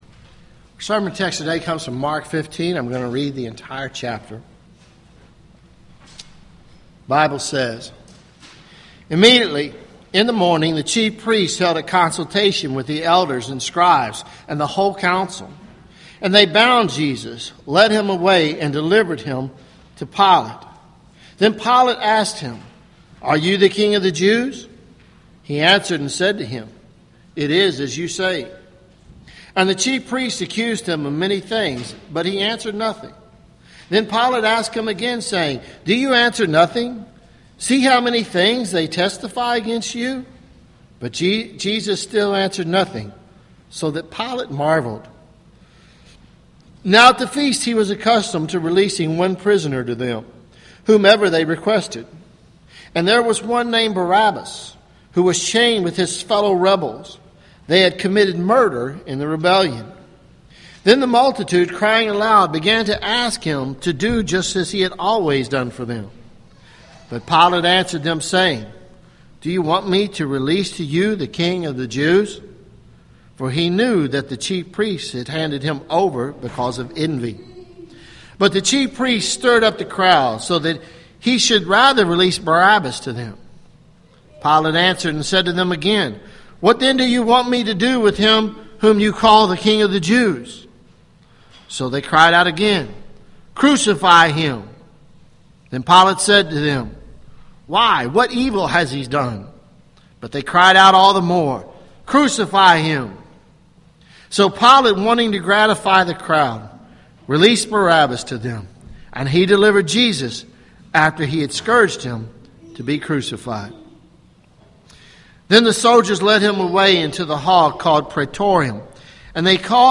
Sermons Mar 29 2015 “A Crucified Messiah?” preached on March 29